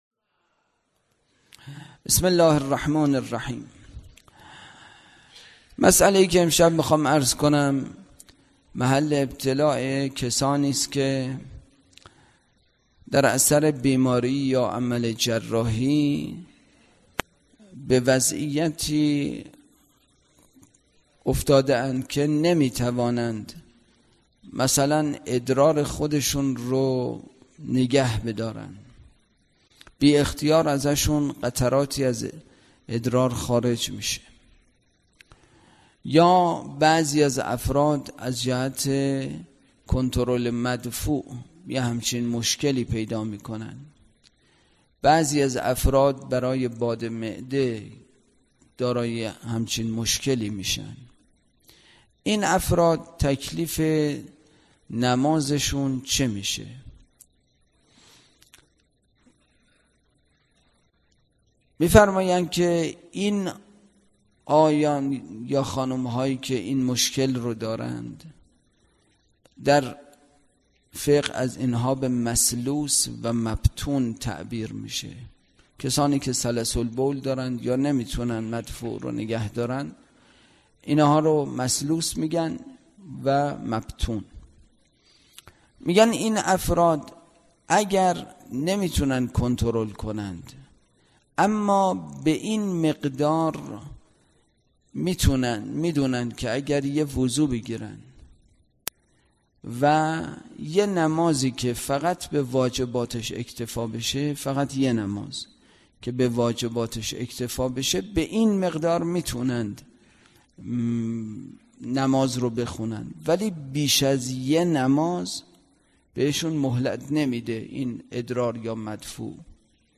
برگزارکننده: مسجد اعظم قلهک